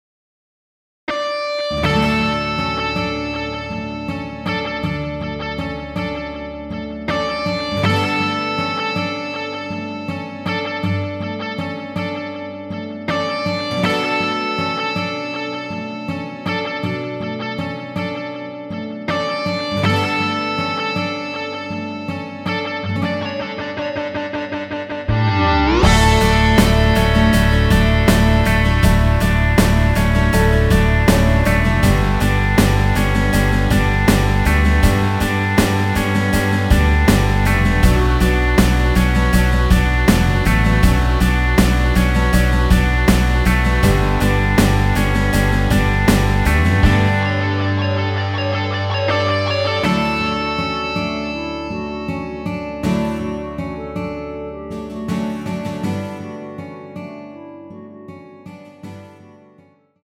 원키에서(-2)내린 멜로디 포함된 MR입니다.
D
앞부분30초, 뒷부분30초씩 편집해서 올려 드리고 있습니다.
중간에 음이 끈어지고 다시 나오는 이유는